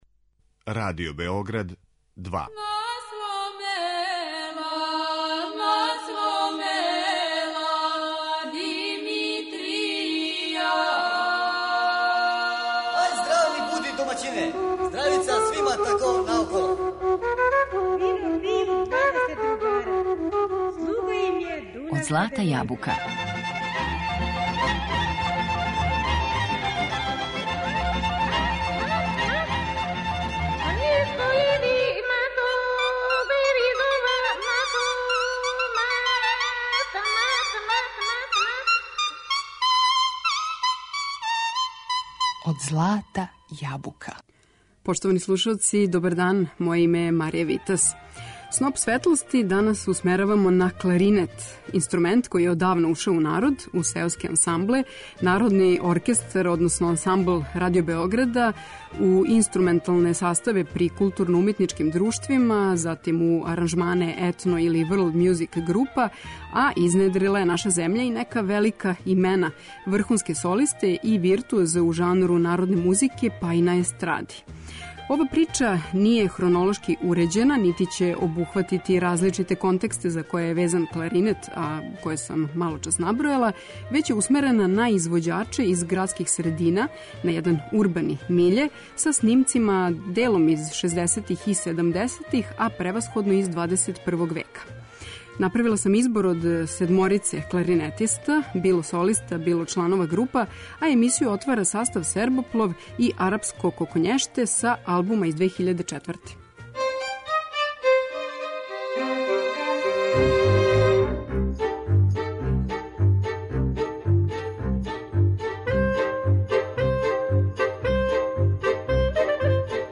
Кларинет у Србији